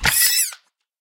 Minecraft Version Minecraft Version 25w18a Latest Release | Latest Snapshot 25w18a / assets / minecraft / sounds / mob / guardian / land_death.ogg Compare With Compare With Latest Release | Latest Snapshot
land_death.ogg